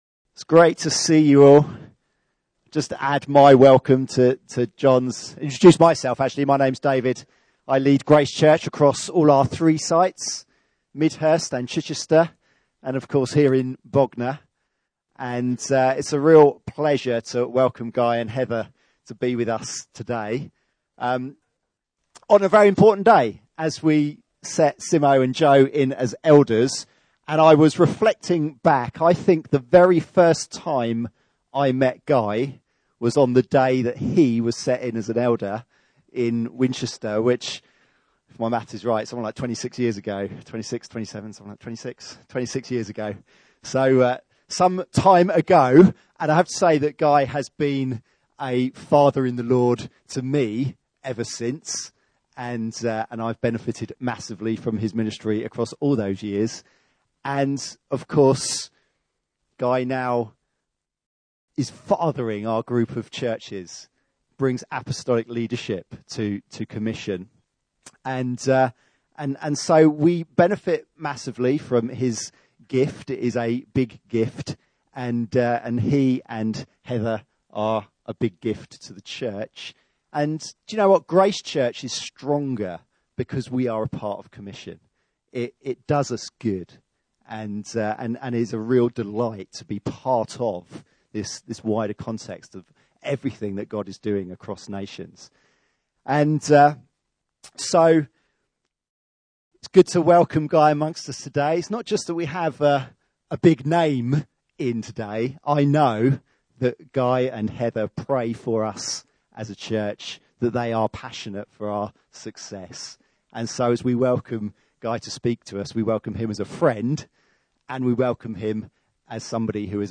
Series: Miscellaneous Sermons 2017